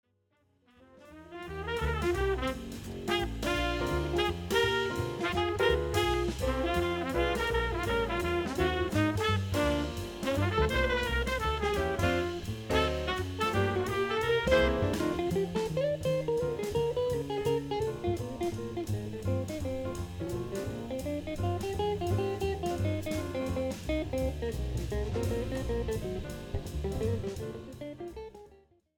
and vibrant Latin and Brazilian jazz melodies
Guitar
Trumpet / Flugelhorn
Tenor Saxophone, Soprano Saxophone, Flute
Piano
Bass
Drums